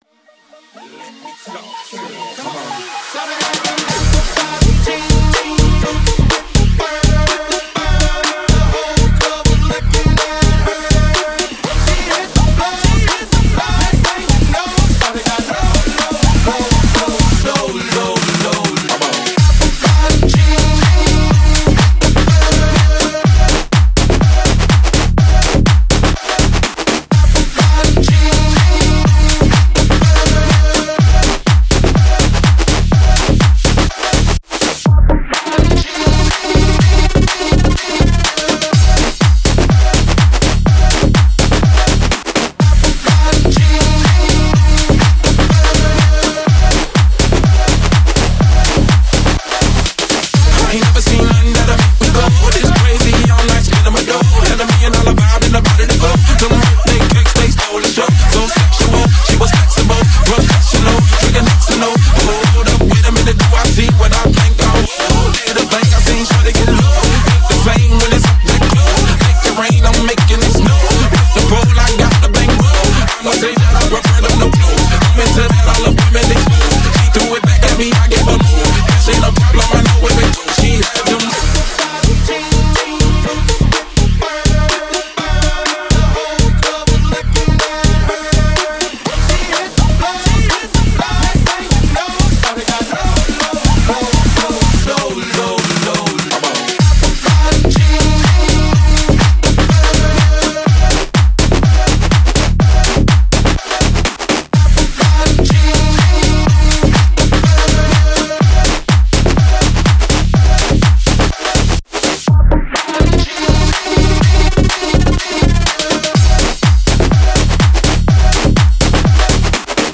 Жанр: Club, Dance, Other